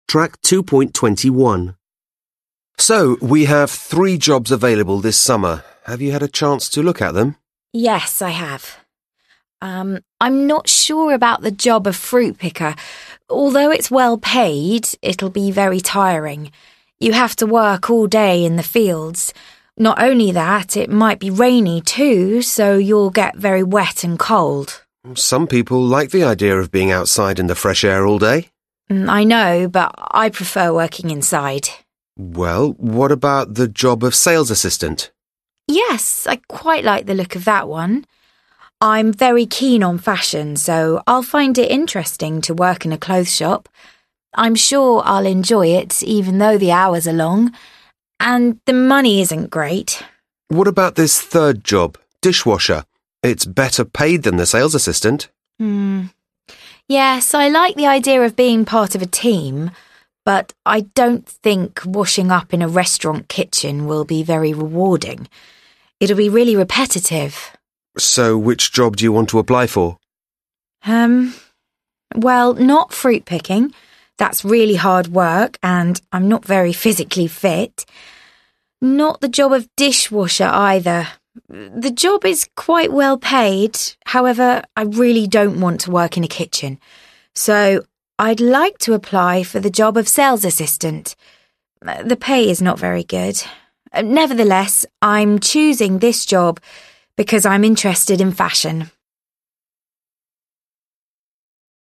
3 (trang 66 Tiếng Anh 10 Friends Global) Listen to a conversation between an examiner and a candidate who wants to get a holiday job in order to save some money.